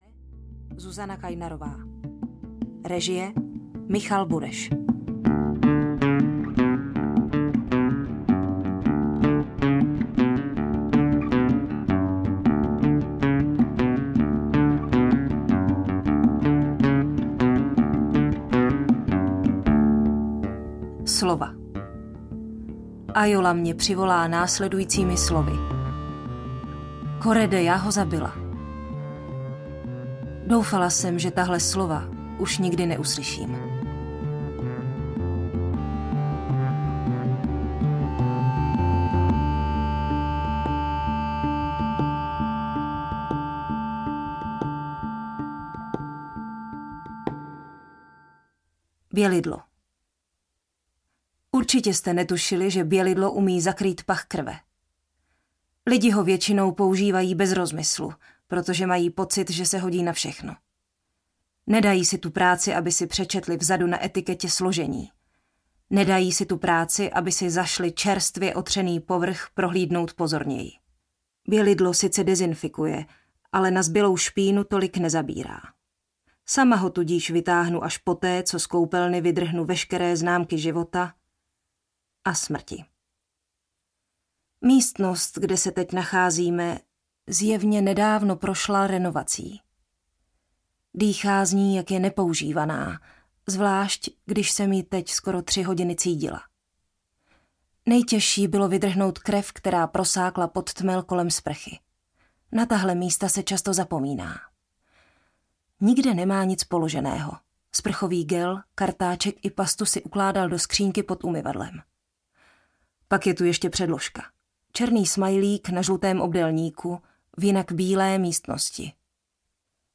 Má sestra je sériový vrah audiokniha
Ukázka z knihy
• InterpretZuzana Kajnarová